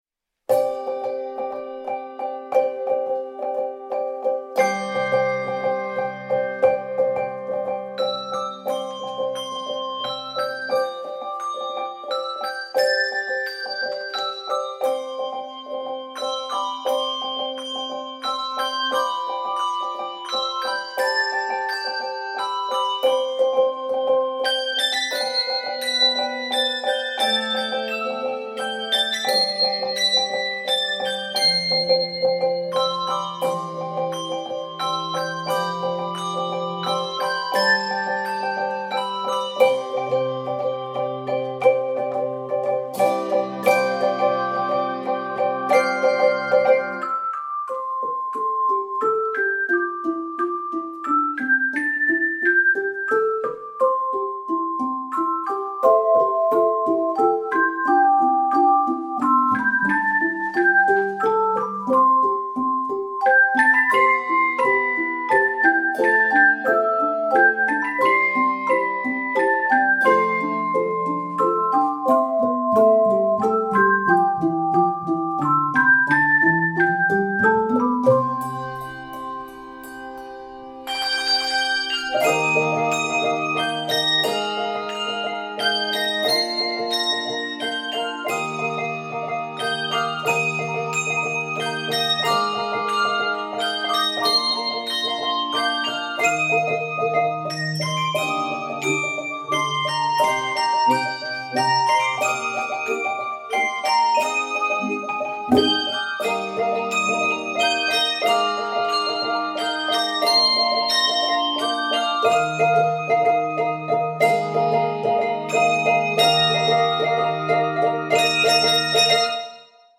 Begins in C Major, then modulates to Eb Major. 59 measures.